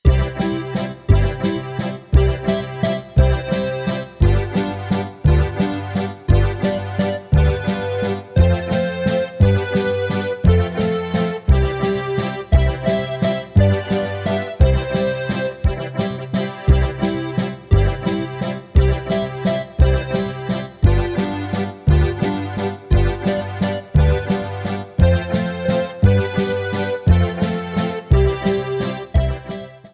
Rubrika: Národní, lidové, dechovka
- valčík
HUDEBNÍ PODKLADY V AUDIO A VIDEO SOUBORECH